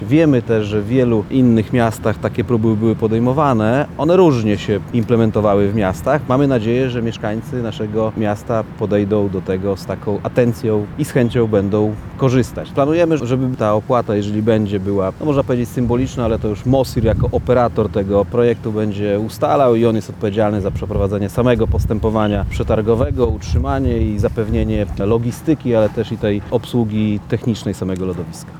– To inicjatywa, która łączy w sobie elementy pilotażu i festiwalu bożonarodzeniowego – mówi zastępca prezydenta miasta Lublin ds. inwestycji i rozwoju Tomasz Fulara.